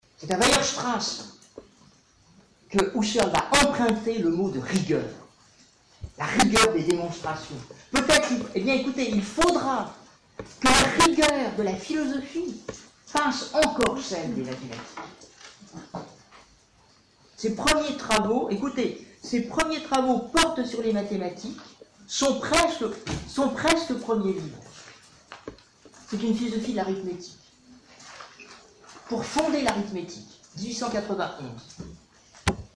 Mirari ² - Fichier "clipping_avant.mp3"